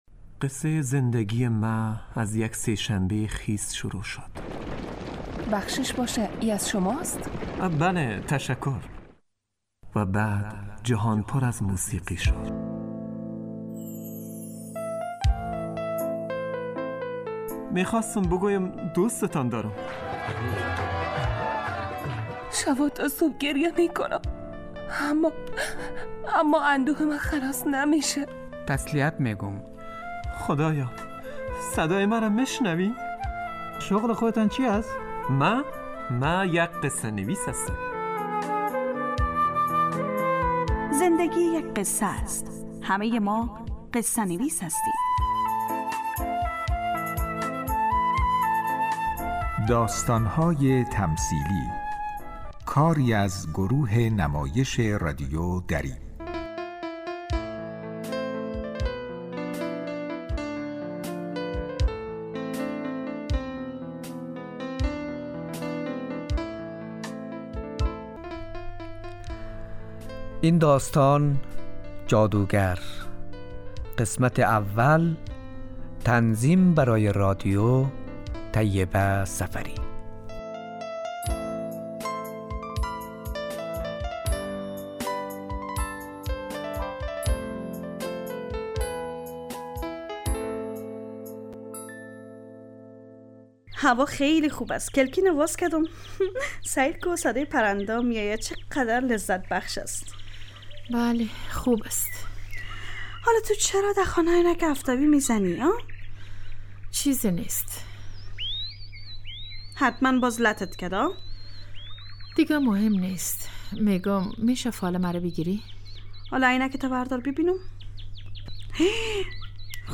داستانهای تمثیلی نمایش 15 دقیقه ای هستند که روزهای دوشنبه تا پنج شنبه ساعت 03:25عصربه وقت افغانستان پخش می شود.